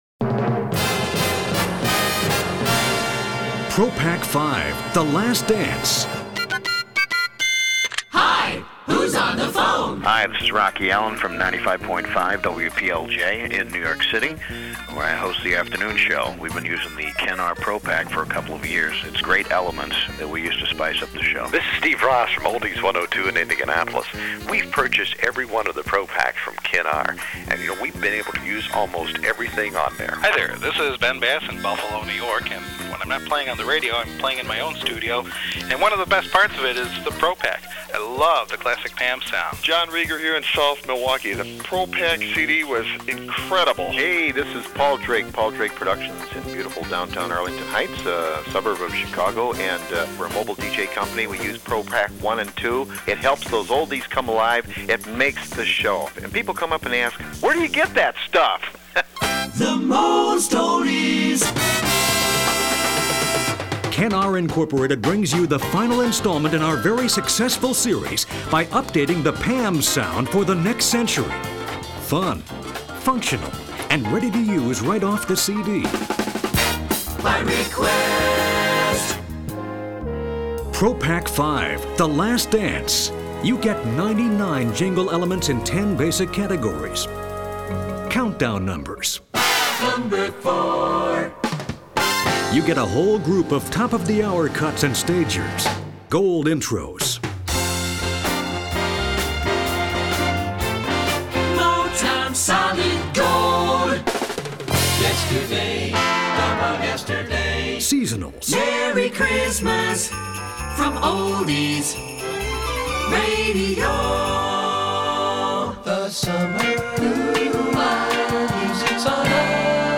Generic Oldies Elements (No Call Letters) on CD Ready-to-Air